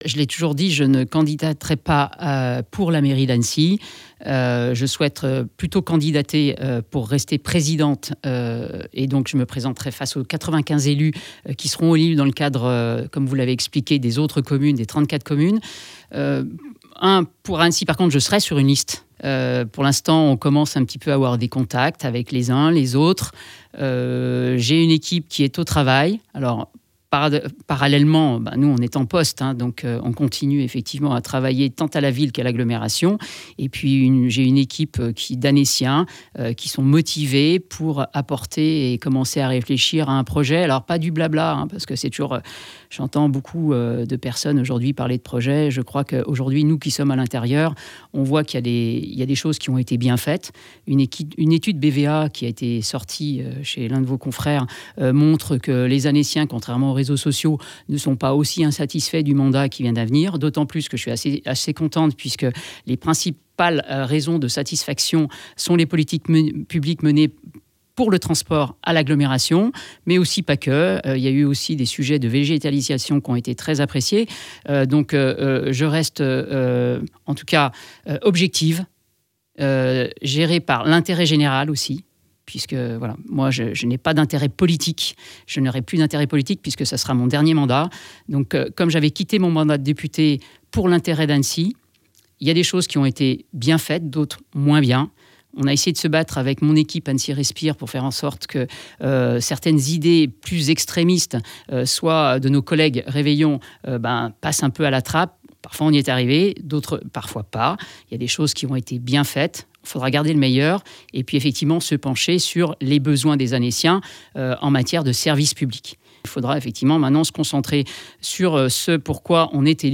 Frédérique Lardet était l’invitée de la Rédac sur ODS Radio :